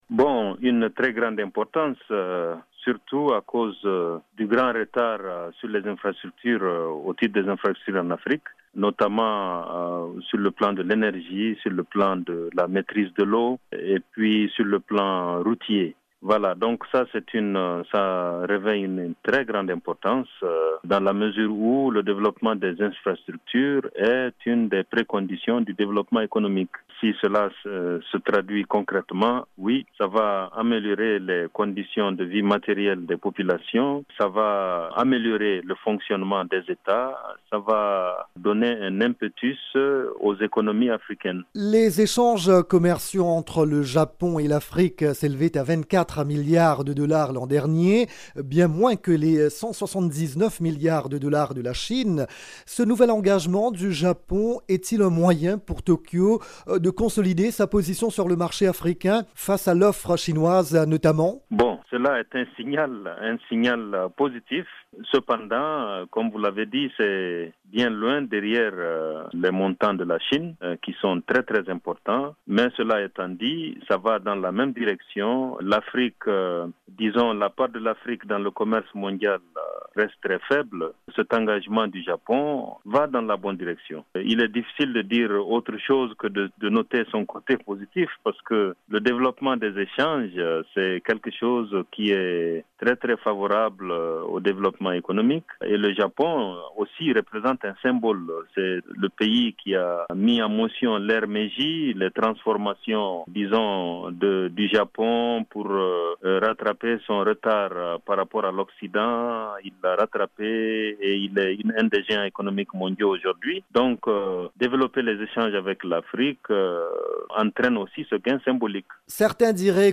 L’économiste